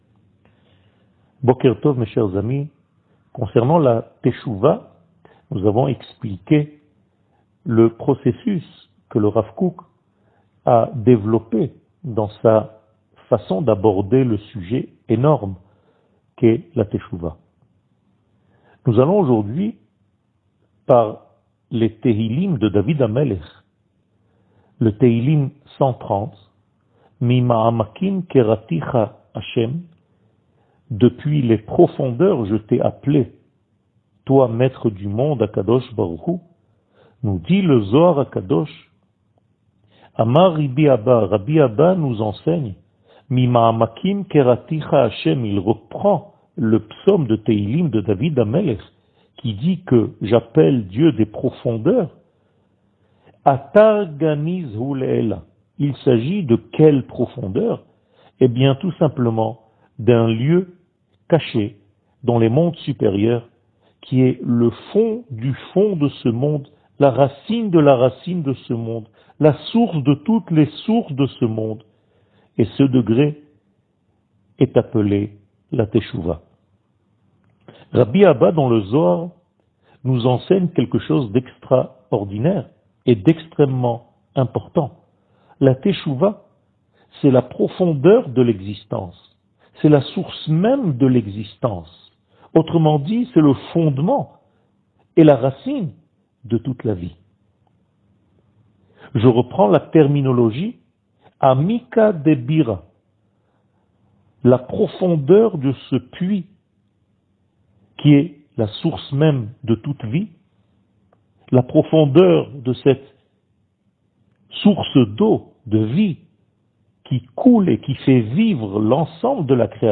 שיעור מ 24 אוגוסט 2021